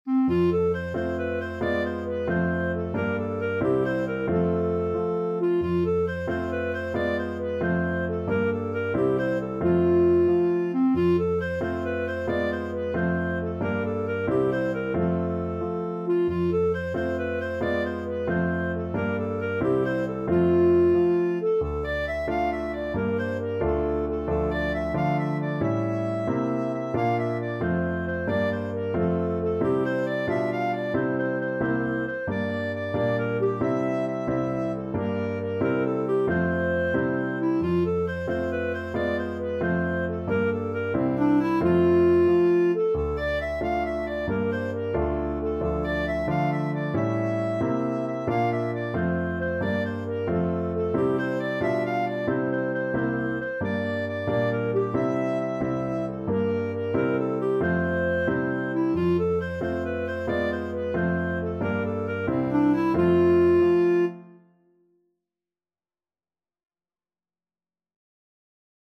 Clarinet
C5-F6
6/8 (View more 6/8 Music)
F major (Sounding Pitch) G major (Clarinet in Bb) (View more F major Music for Clarinet )
Moderato . = 90
Traditional (View more Traditional Clarinet Music)